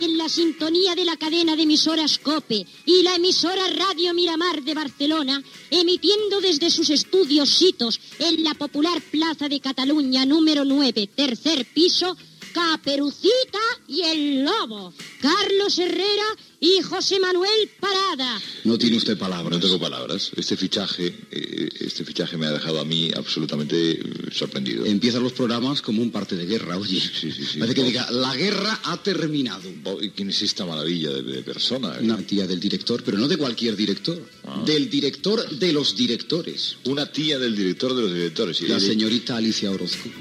Careta
Entreteniment